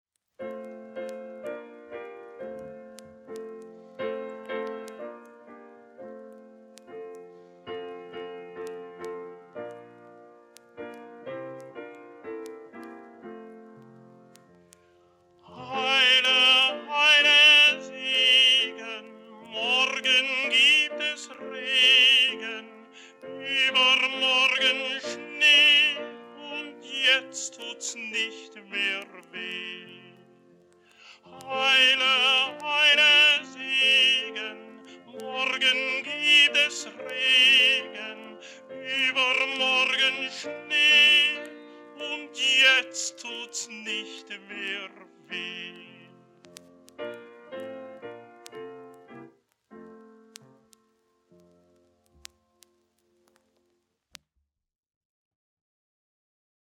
kurzer deutschsprachiger Kinderreim